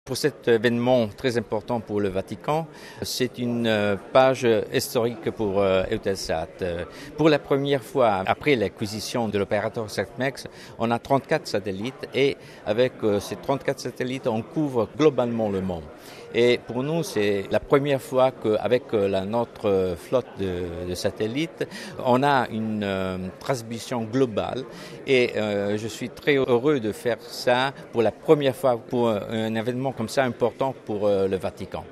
interrogé par